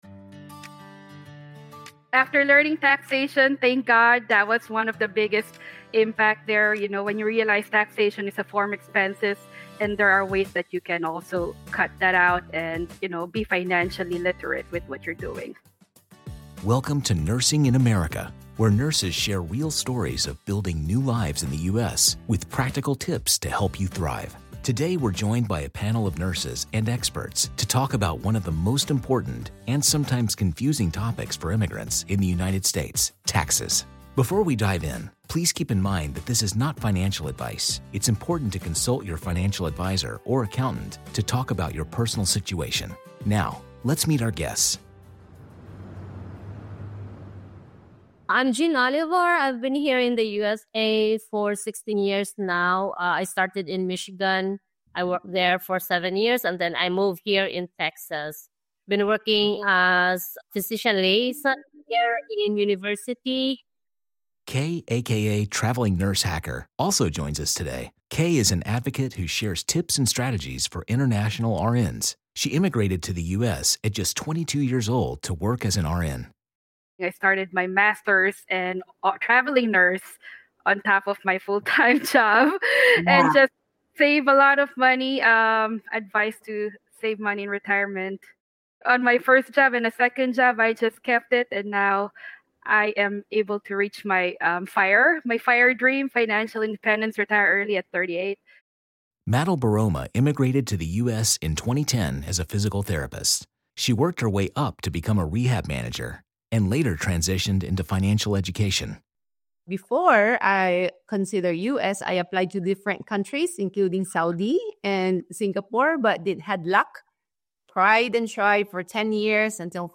Join us as we sit down with a panel of experts and immigrant healthcare professionals to demystify the U.S. tax system. From understanding your W-4 to leveraging tax credits and deductions, this episode is packed with advice to help you take control of your finances.